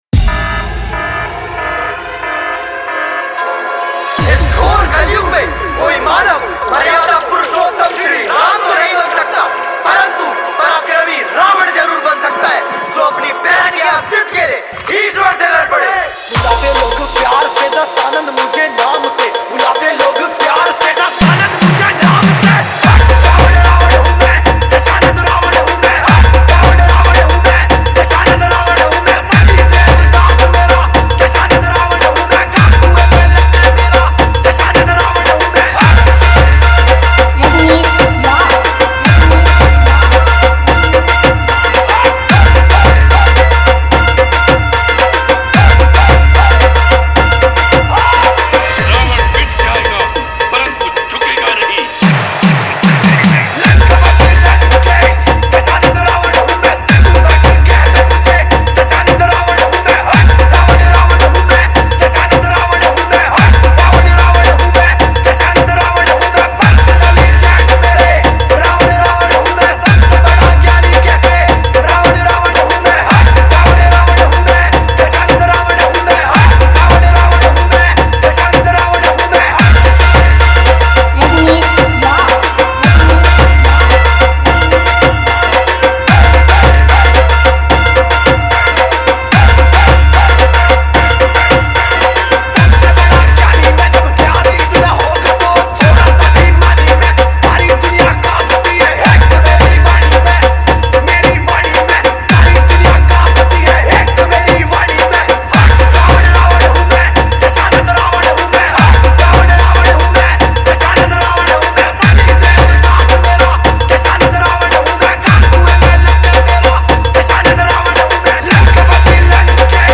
All Bhakti Dj Remix Songs » Ramnavami Dj Remix Songs